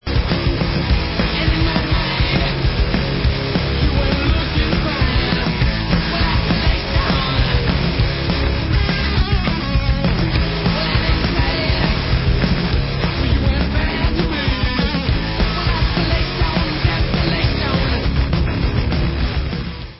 sledovat novinky v oddělení Rock/Alternative Metal